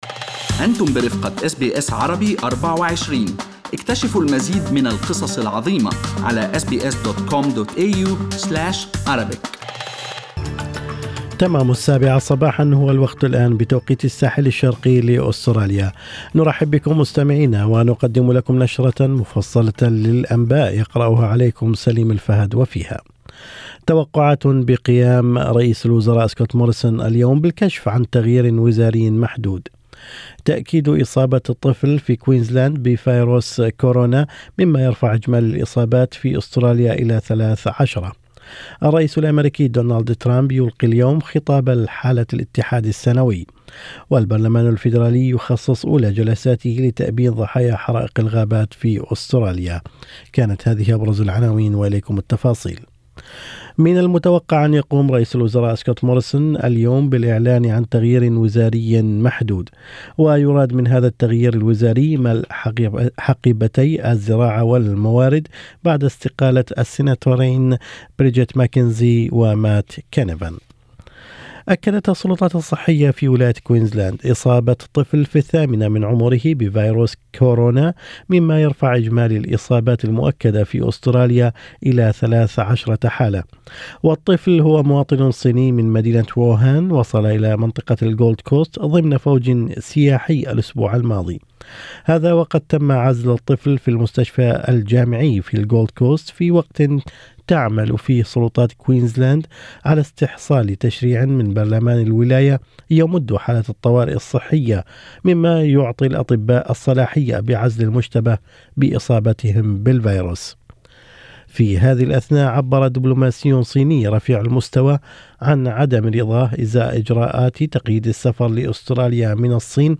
نشرة أخبار الصباح 5/2/2020
Arabic News Bulletin Source: SBS Arabic24